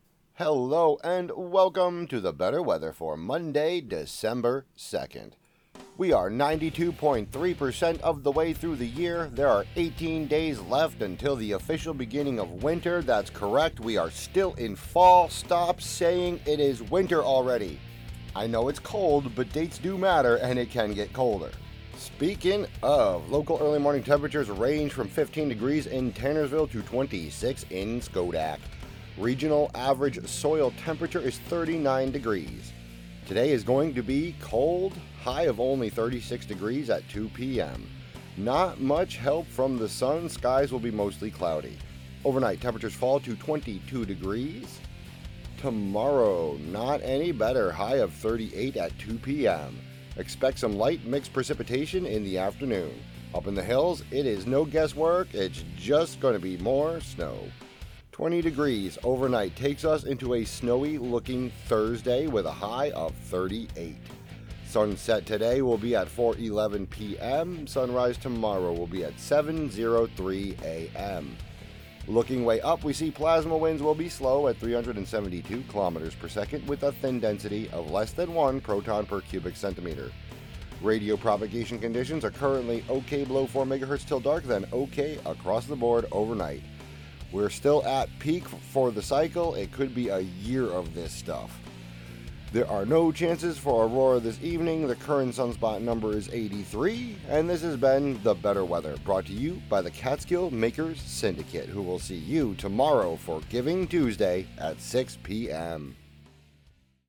broadcasts